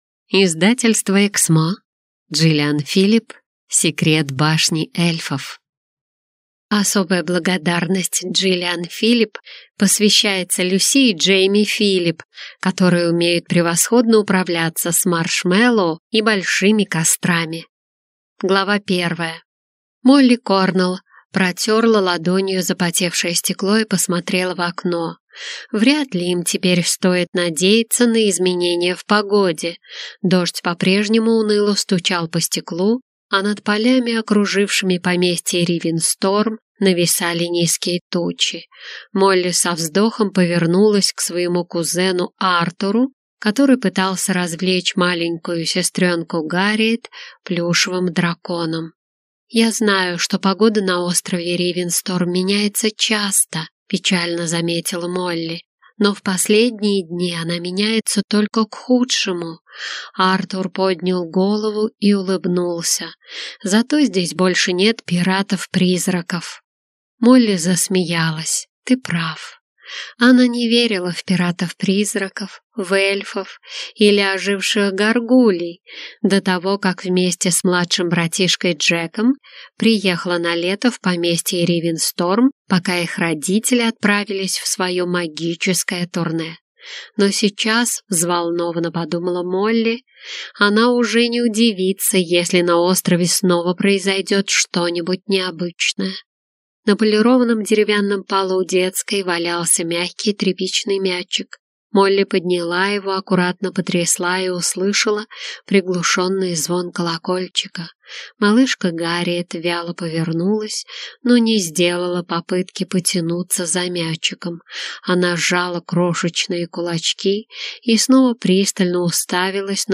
Аудиокнига Секрет башни эльфов | Библиотека аудиокниг